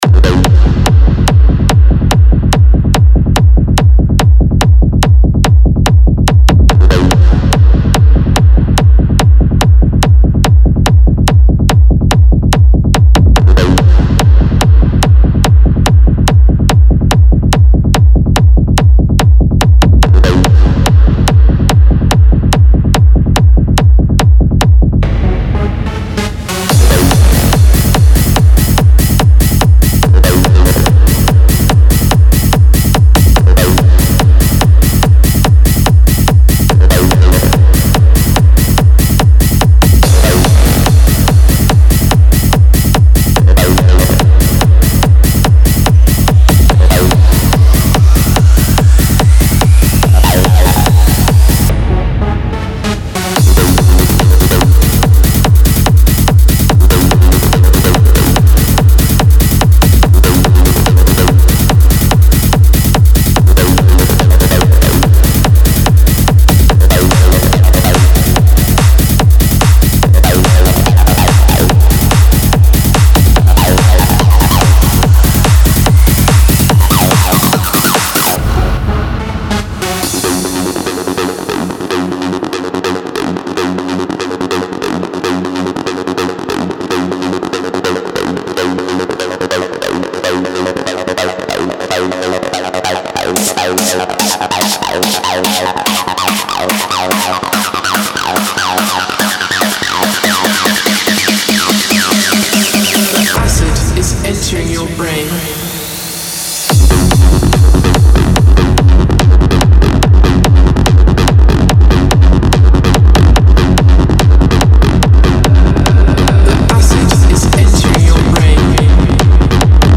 • Жанр: Techno